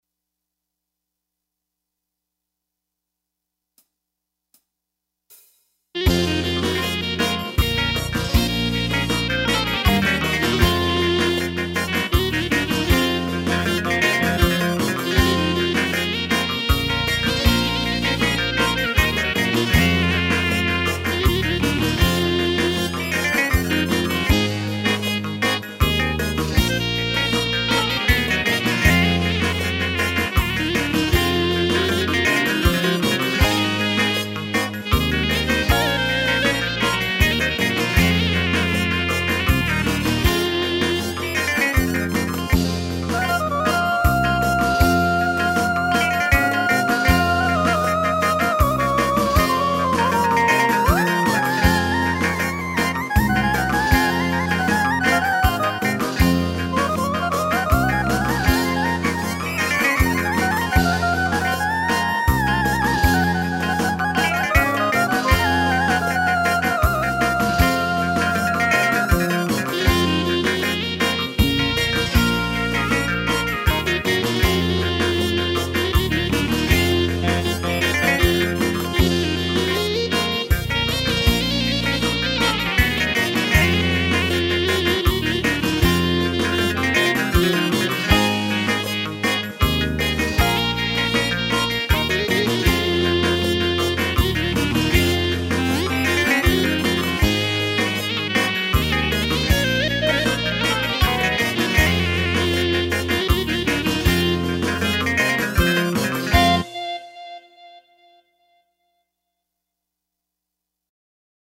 Below are musical recordings of me playing music.
Tsamiko